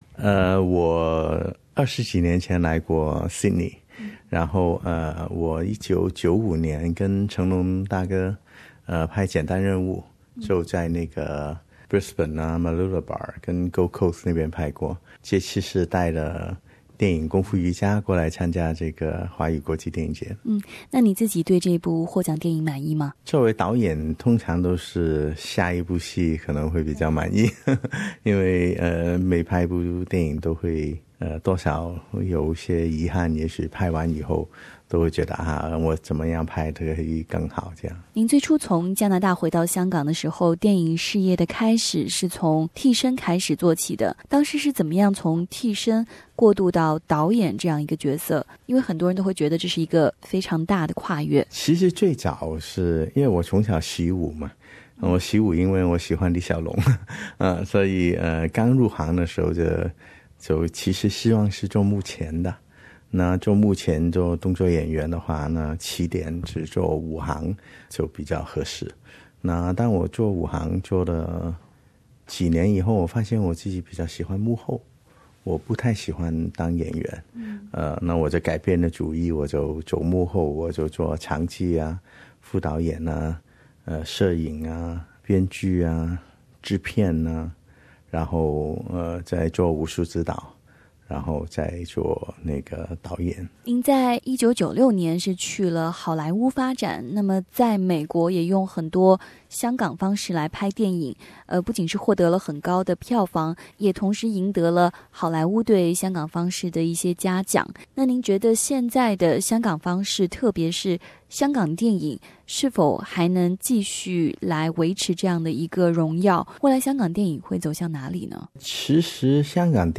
【SBS专访】华语电影节金龙奖最佳合拍片导演唐季礼